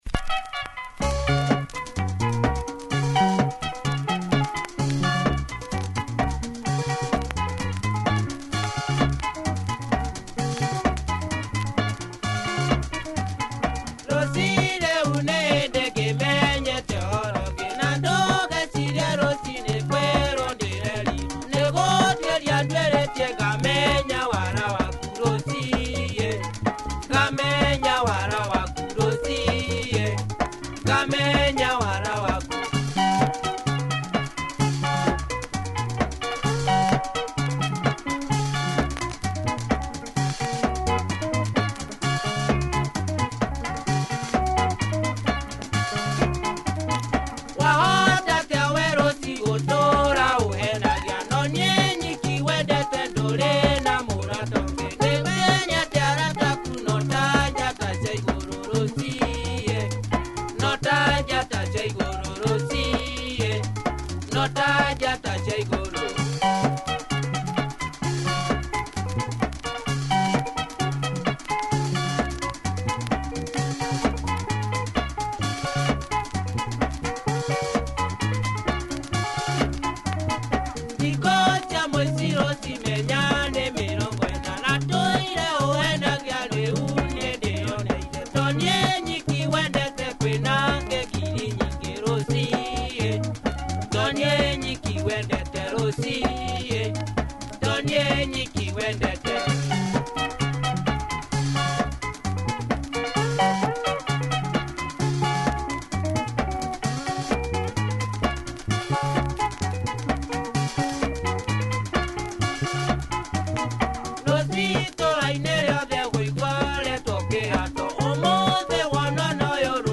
Kickass drums on this Benga treat by a group